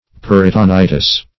Peritonitis \Per`i*to*ni"tis\, n. [NL.